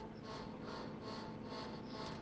Wasserkühlung brummt
Meine Wasserkühlung brummt bei spielen mit großer Auslastung (warzone,coldwar).
Wie kann ich verhindern das mein PC abstürzt und wie kann ich das laute brummen Ausschalten?